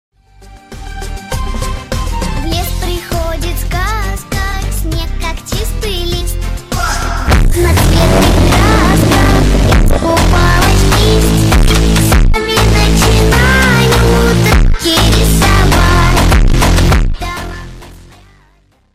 Tesla or Brutal BMW M3? sound effects free download